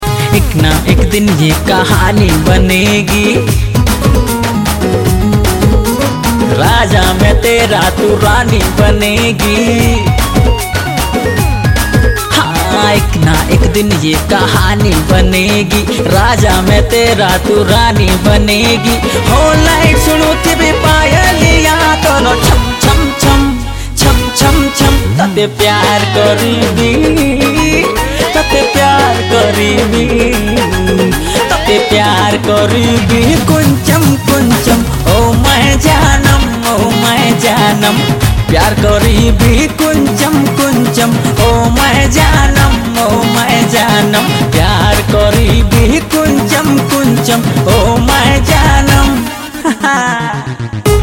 Odia Ringtones
dance song